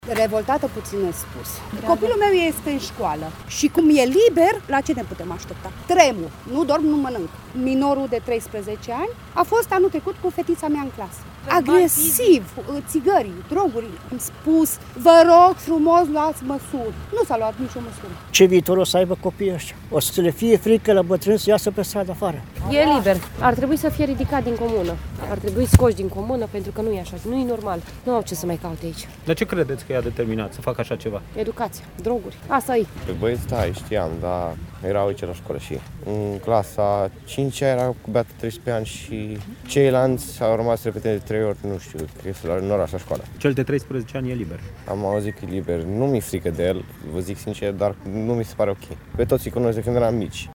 „O să le fie frică bătrânilor să iasă pe stradă”, consideră un bătrân
22ian-18-CORESPTM-voxuri-crimaciune-CENEI-.mp3